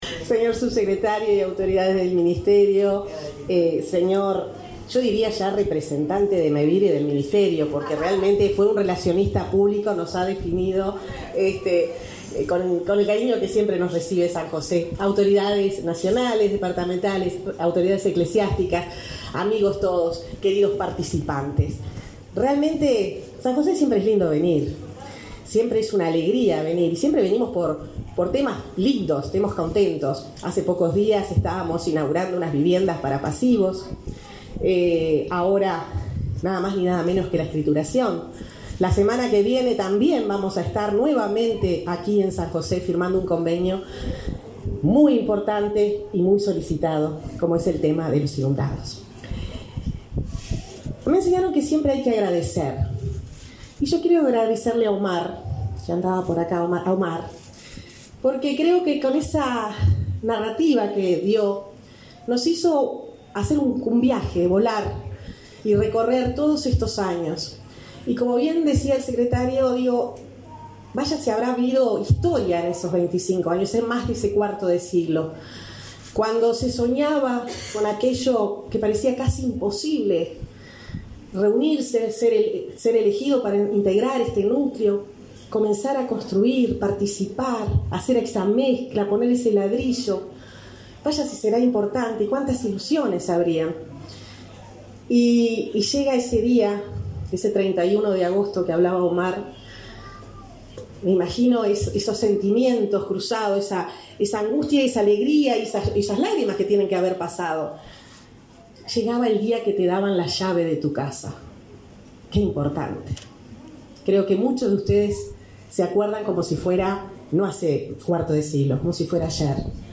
Palabras de la ministra de Desarrollo Social, Irene Moreira
Mevir realizó, este 20 de diciembre, el acto de 60 escrituras colectivas de viviendas en la localidad de Puntas de Valdez, departamento de San José.